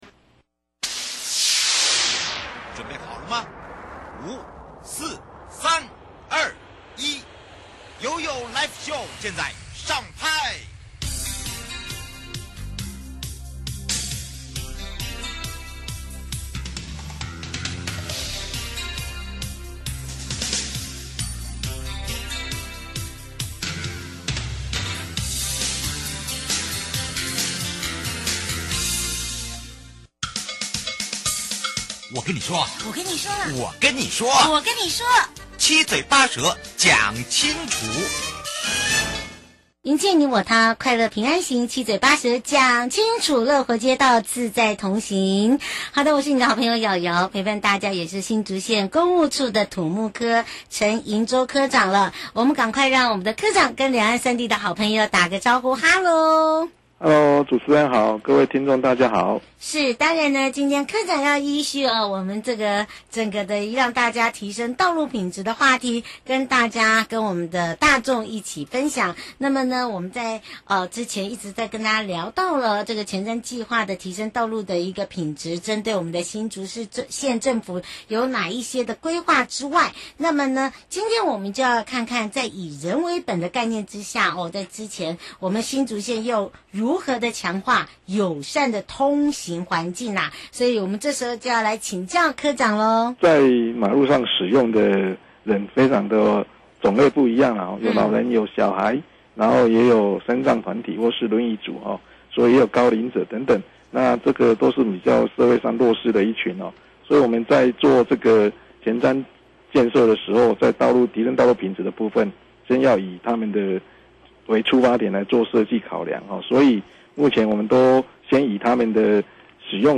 受訪者： 營建你我他 快樂平安行七嘴八舌講清楚 在以人為本的概念下，新竹縣要如何強化友善的通行環境？另外想要請教新竹縣，對於前瞻—提升道路品質計畫的推動感想。